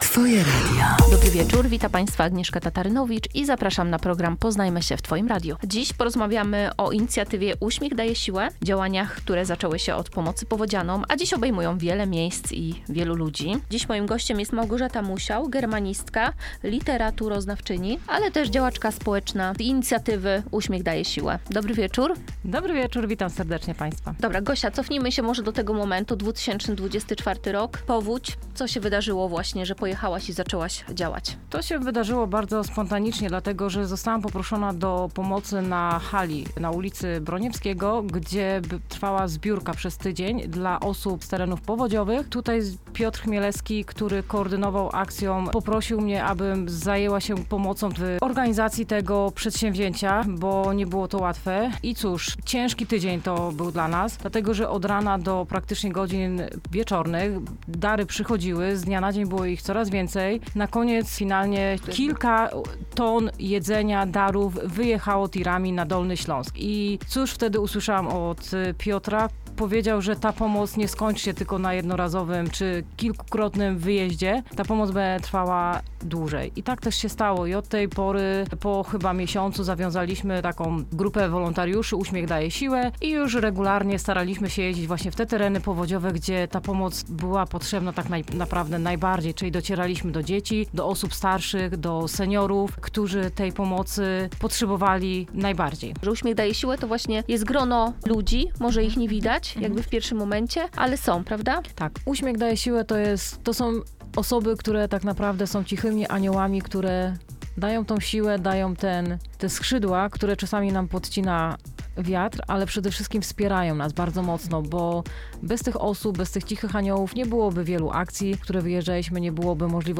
Rozmowa o inicjatywie „Uśmiech Daje Siłę” i działaniach, które zaczęły się od pomocy powodzianom, a dziś łączą ludzi, budują relacje i przypominają, jak wiele mogą znaczyć małe gesty.